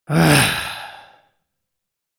Frustrated Sigh Sound Effect
Description: Frustrated sigh sound effect. Exhale sound effect expressing frustration, irritation, and emotional tension.
Frustrated-sigh-sound-effect.mp3